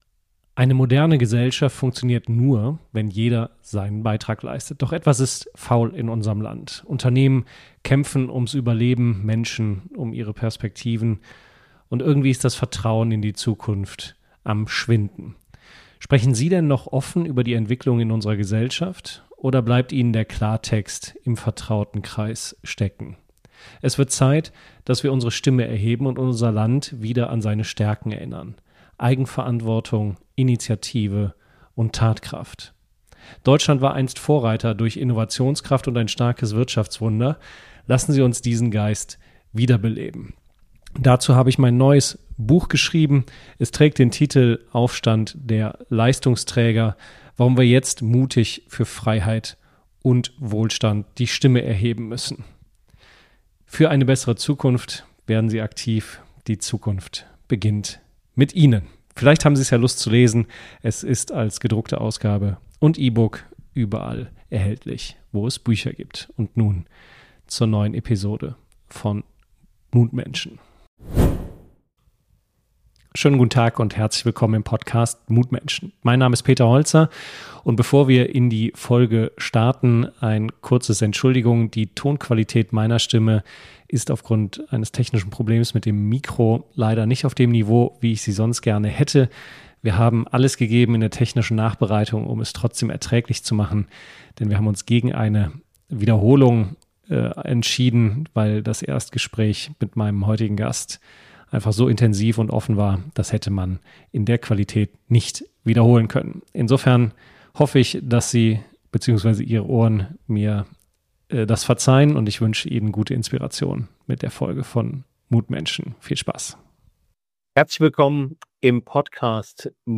Andy Holzer ("Blind Climber") | Mutmenschen #40 ~ Mutmenschen — Gespräche über Mut, Freiheit und Veränderung Podcast